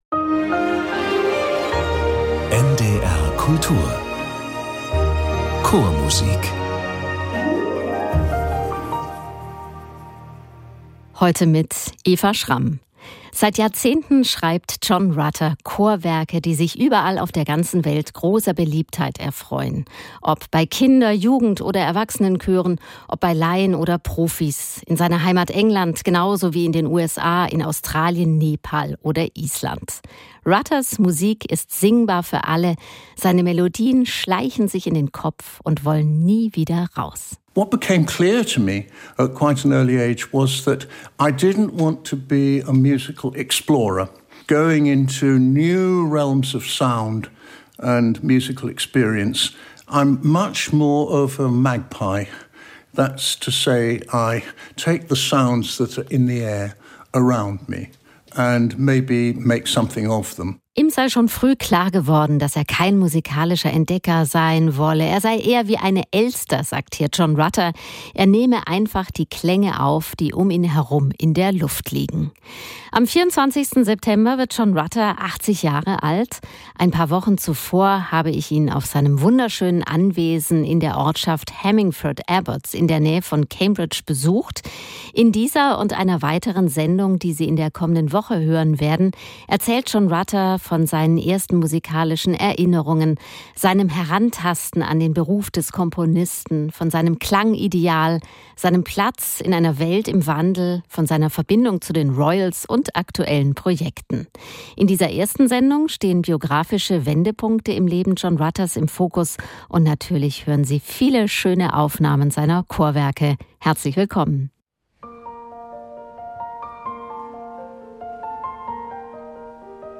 Ein Hausbesuch mit Gesprächen über die Liebe zur Chormusik, entscheidende Lebensstationen und die aktuelle Weltlage.